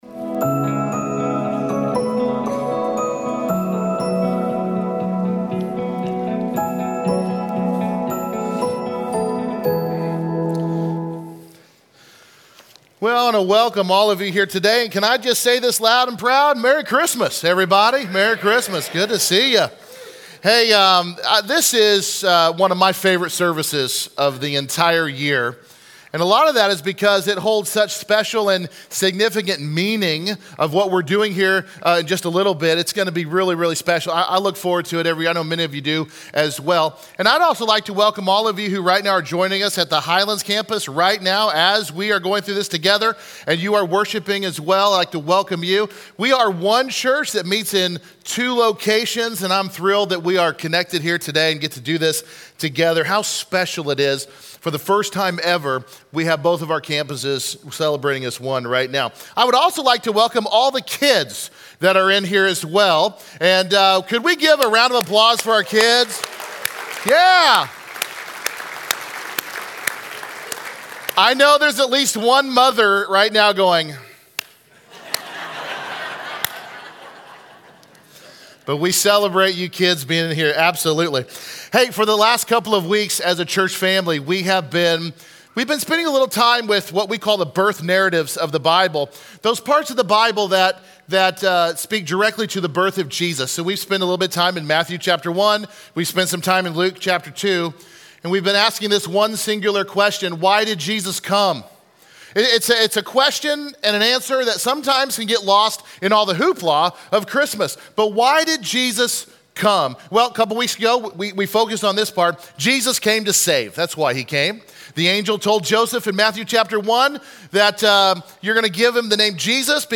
He came to save, to redeem, and to change lives! Join us today for a very special Christmas Candle Lighting Service.